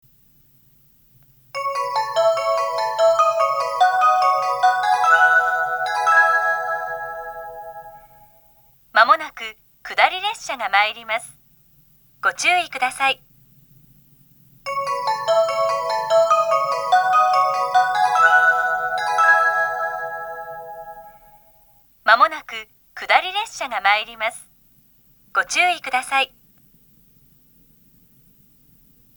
20191月中旬頃に放送装置が更新され、メロディーで始まるタイプの接近放送になりました。
接近放送
女性による接近放送です。
2回目鳴動します。